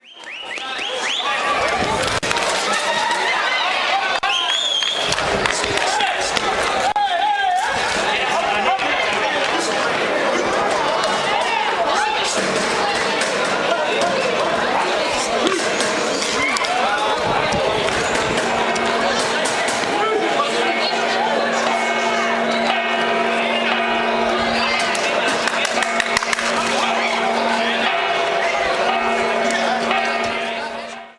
Sonidos ambiente de Nava: